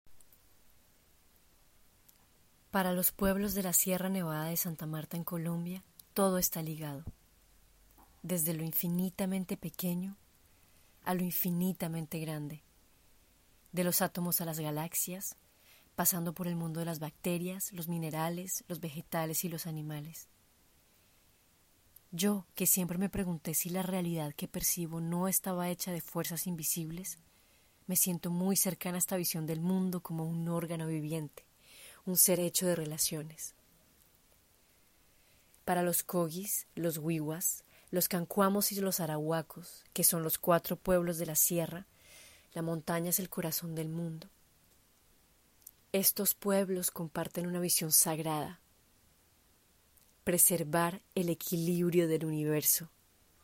Voix OFF - Espagnol - Historia de Anokua
25 - 50 ans - Contralto